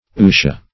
Search Result for " utia" : The Collaborative International Dictionary of English v.0.48: Utia \U"ti*a\, n. [NL.]